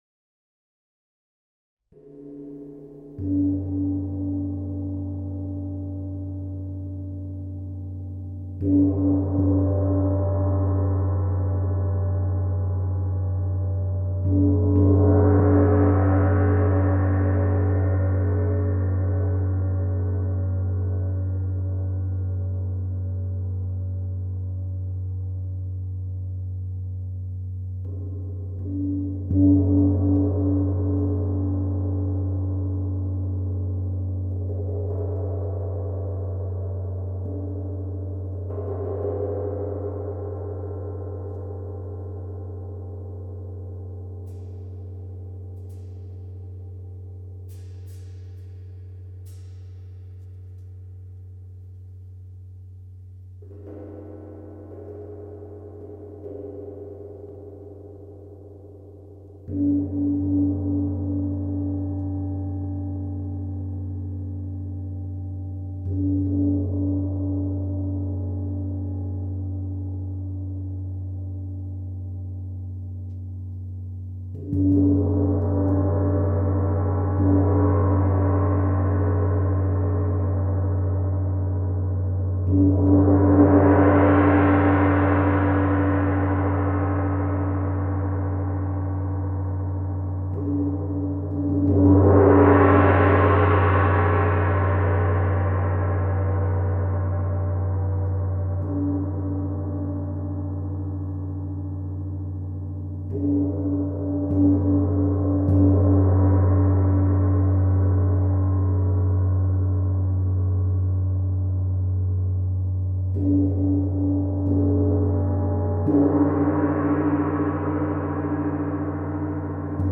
Meinl Sonic Energy Chau Tam Tam - 26"/66 cm + Beater & Cover (CH-TT26)